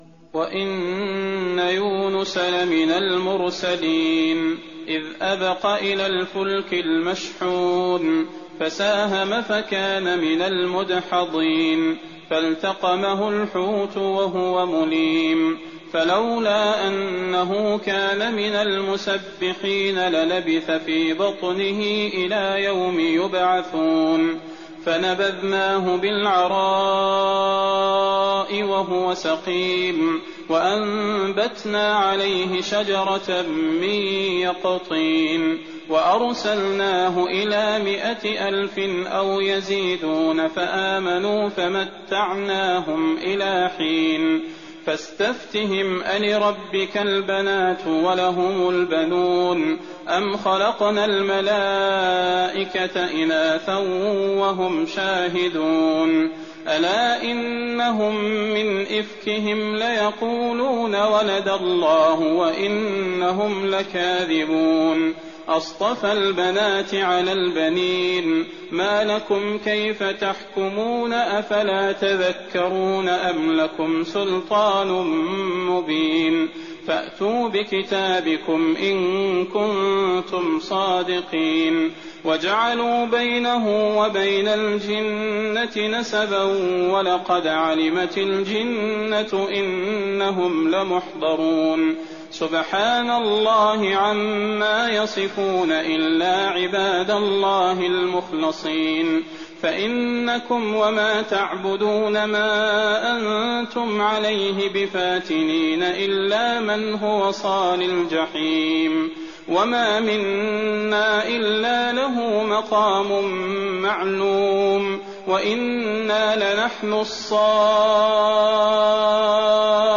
تراويح ليلة 22 رمضان 1419هـ من سور الصافات (139-182) و ص و الزمر (1-31) Taraweeh 22nd night Ramadan 1419H from Surah As-Saaffaat and Saad and Az-Zumar > تراويح الحرم النبوي عام 1419 🕌 > التراويح - تلاوات الحرمين